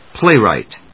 音節pláy・wrìght 発音記号・読み方
/‐rὰɪt(米国英語), ˈpleɪˌraɪt(英国英語)/